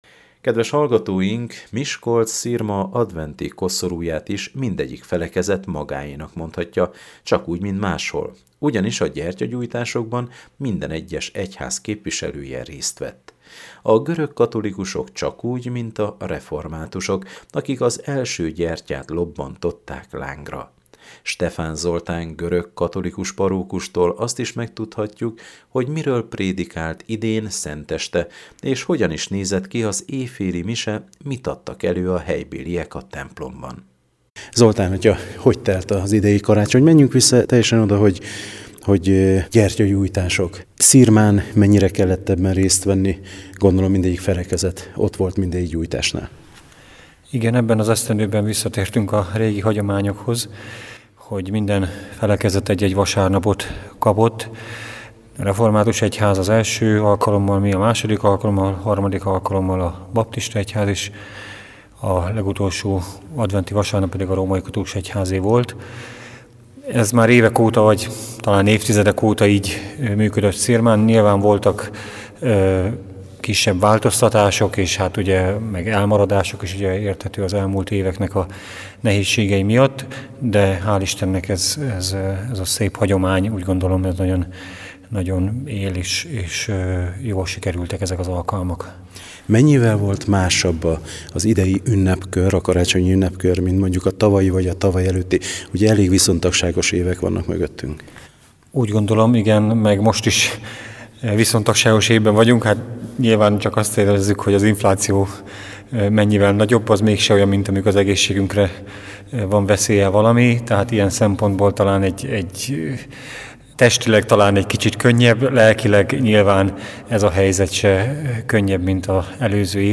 Szenteste a Miskolc-Szirmai Görög Katolikus Templomban